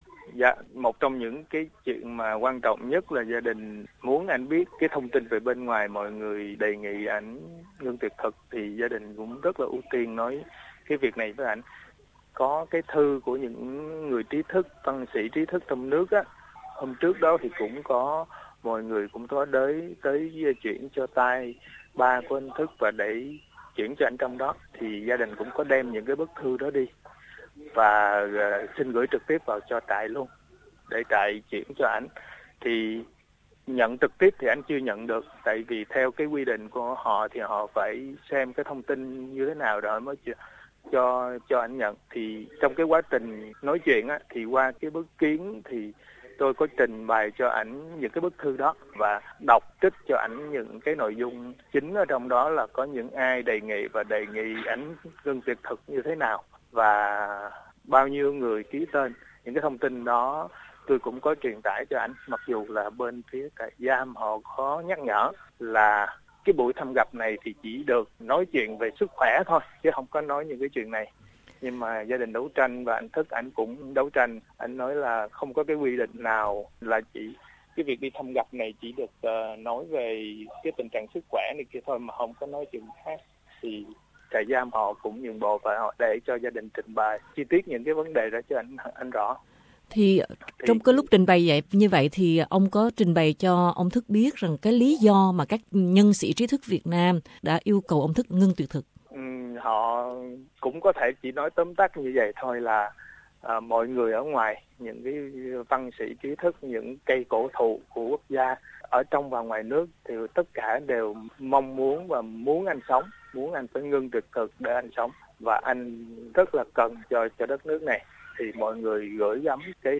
trả lời SBS qua điện thoại từ Vinh